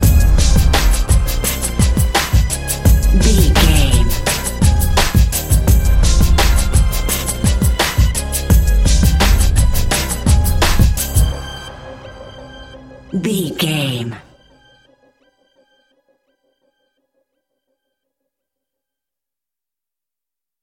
Aeolian/Minor
drum machine
synthesiser
hip hop
soul
Funk
neo soul
acid jazz
r&b
energetic
cheerful
bouncy
funky